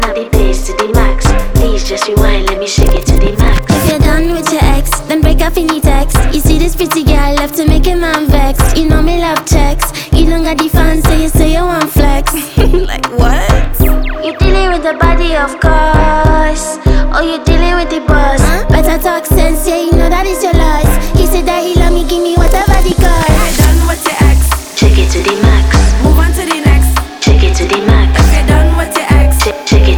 African Dancehall